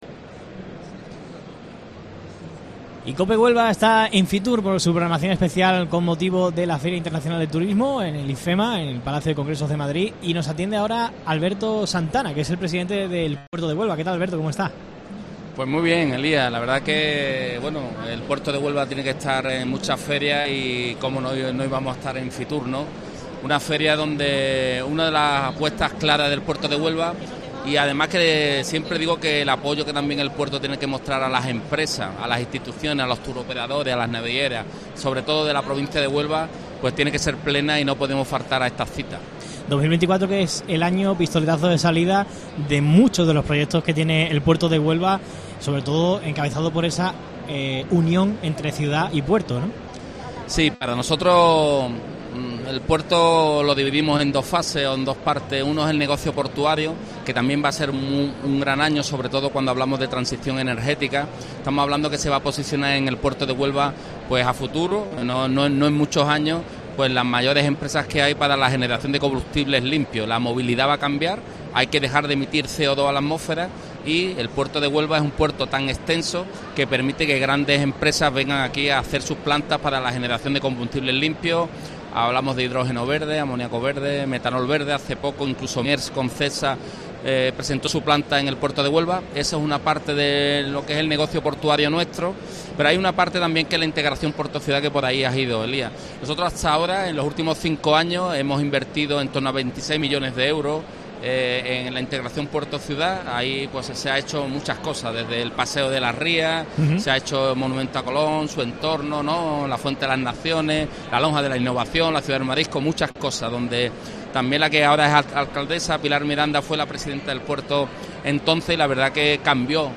Entrevista completa a Alberto Santana, presidente del Puerto de Huelva